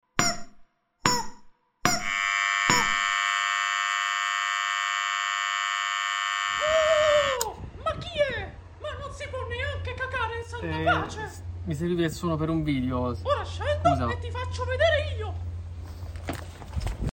Rainbow Friends jumpscare sound real sound effects free download